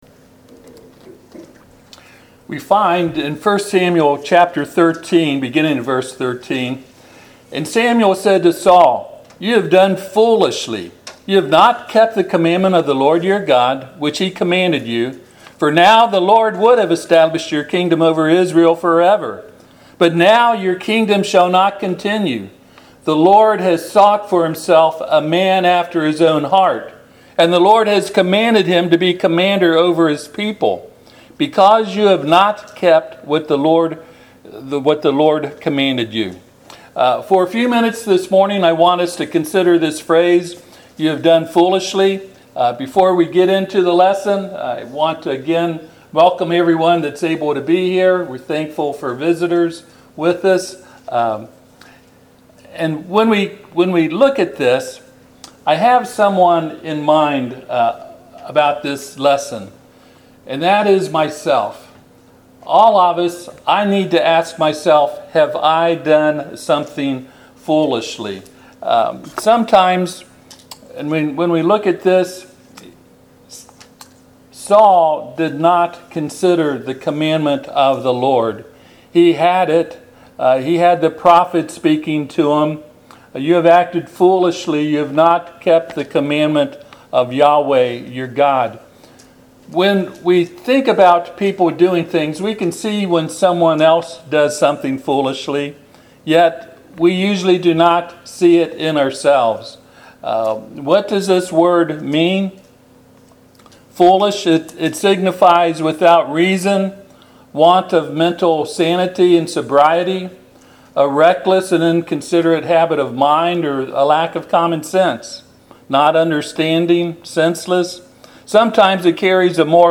1 Samuel 13:13-14 Service Type: Sunday AM 1Samuel 13:13 And Samuel said to Saul